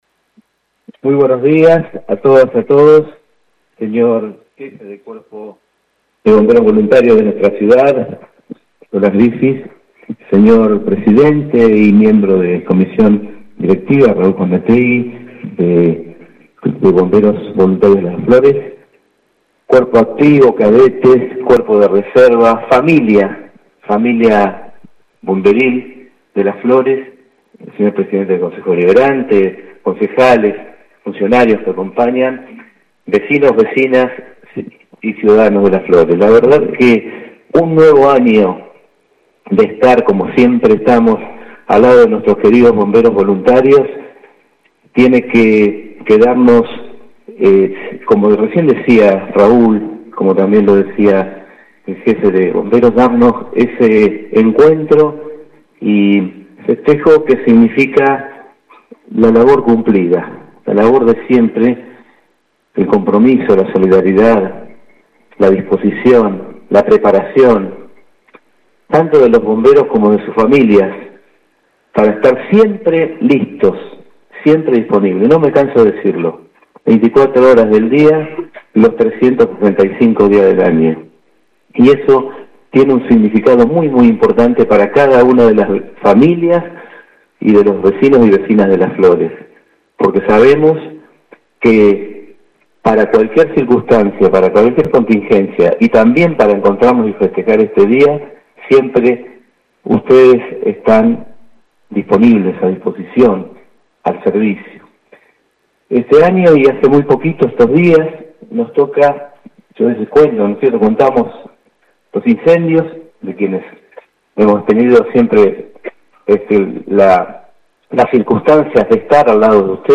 Con la presencia de la actual Comisión Directiva, integrantes del Cuerpo Activo, Cuerpo de Reserva y autoridades municipales se llevó a cabo en la mañana de hoy en el cuartel de calle Pueyrredón el acto conmemorativo por el Día del Bombero Voluntario.
Intendente Alberto Gelené: